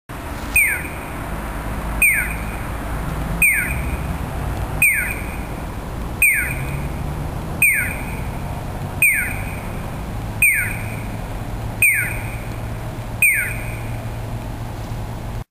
佐賀駅前（もちろんＪＲです）の音響信号は朝っぱらからかなりの音量で流れてくれます。午前６時１０分ごろに撮影したものですが建物などで、実際は音がはね返ってくるほど大きいです。